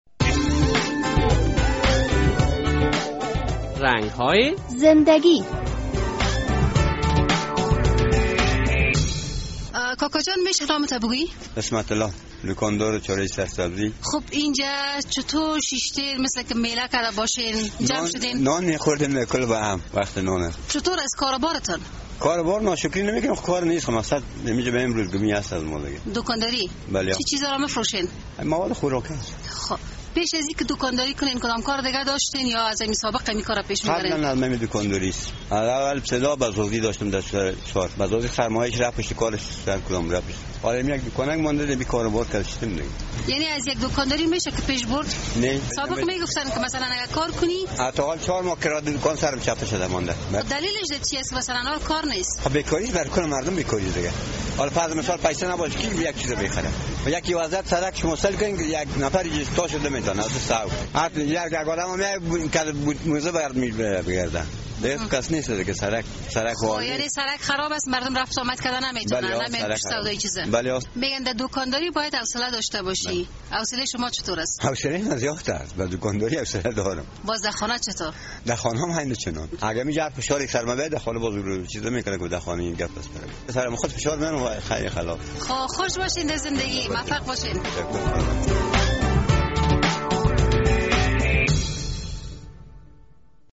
بقیه حرف ها را از زبان خودش بشنوید: